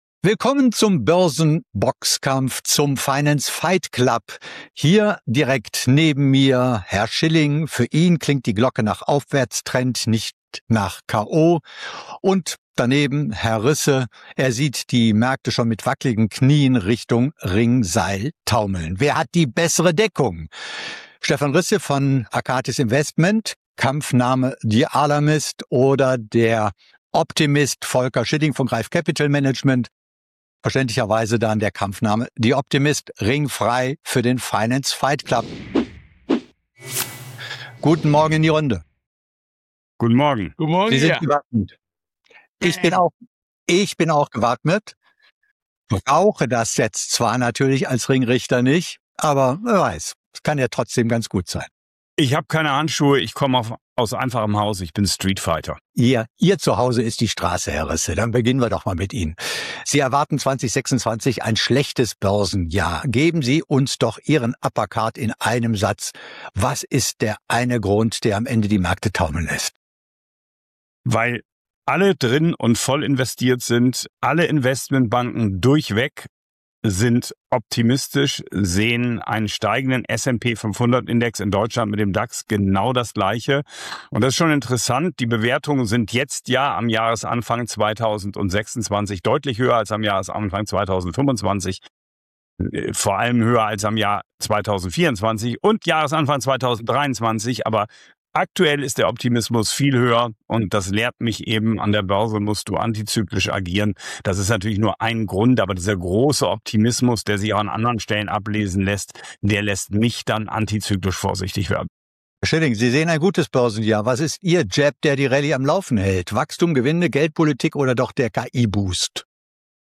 Die beiden liefern einen pointierten Schlagabtausch über Bewertungen und Stimmung, Marktbreite, KI als Renditetreiber oder Blasenrisiko, mögliche IPO-Euphorie und die Frage, ob es für einen Rückschlag überhaupt ein großes Ereignis braucht – oder ob der Markt „reif“ ist und schon kleine Nachrichten reichen. Am Ende gibt’s ein klares Votum: S&P 500 höher oder tiefer zum Jahresende?